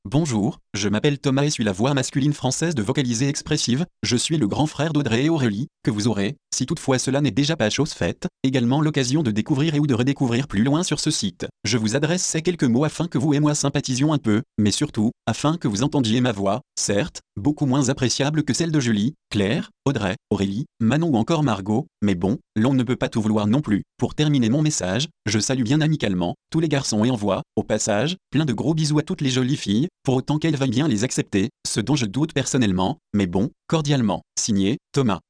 Texte de démonstration lu par Thomas, voix masculine française de Vocalizer Expressive
Écouter la démonstration d'Thomas, voix masculine française de Vocalizer Expressive
Thomas.mp3